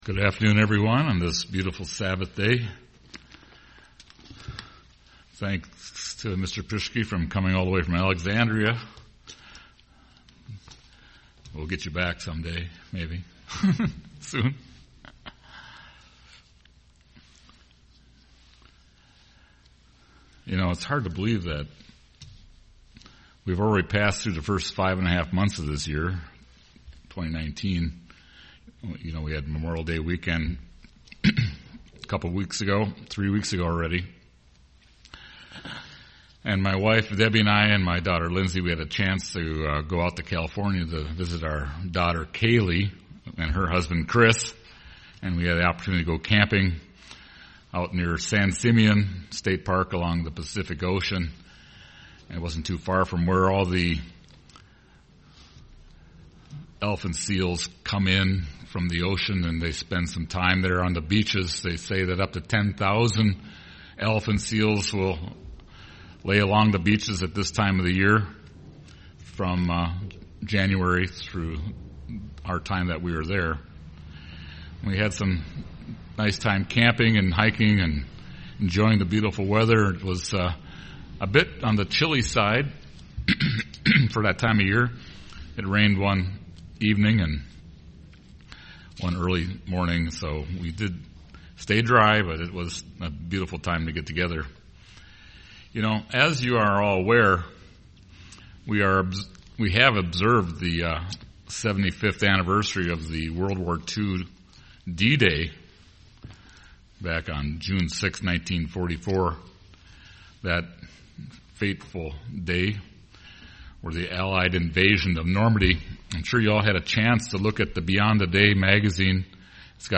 Given in Twin Cities, MN
UCG Sermon hope for the future Studying the bible?